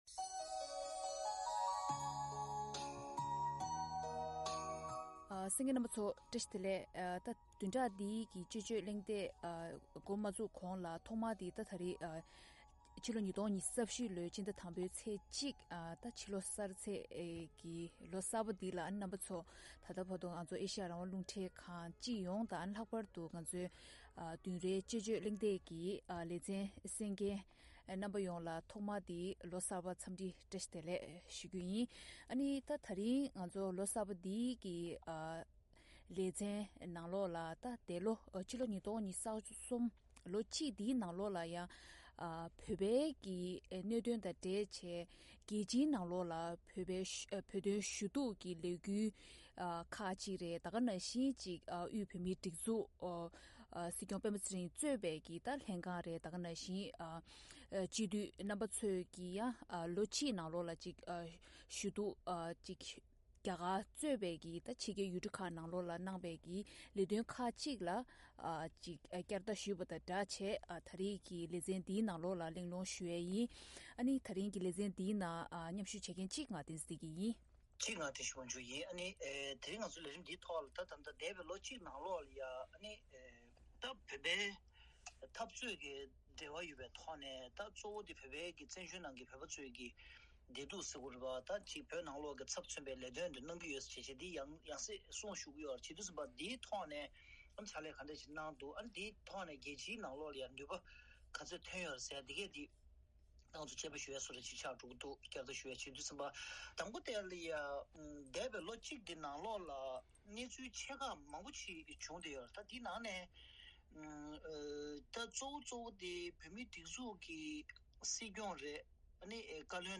དཔྱད་གླེང་ཞུས་པ་ཞིག་གསན་རོགས་གནང་།